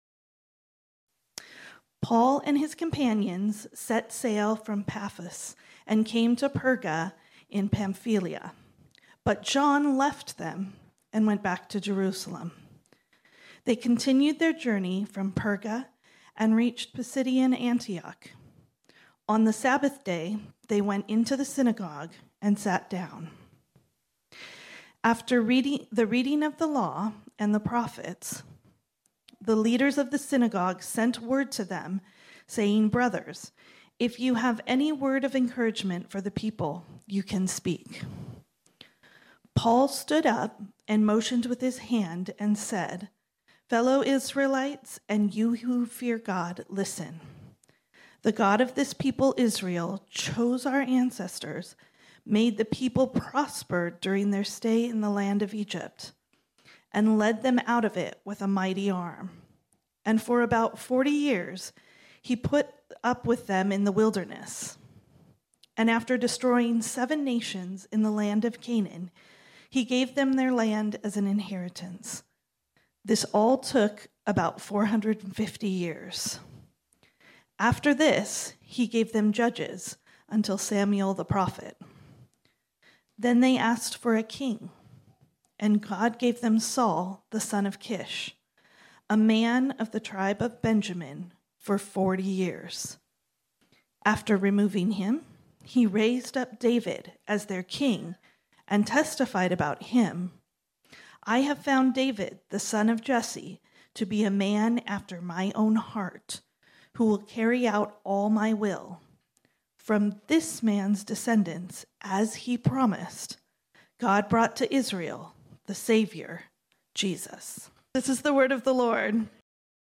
This sermon was originally preached on Sunday, April 2, 2023.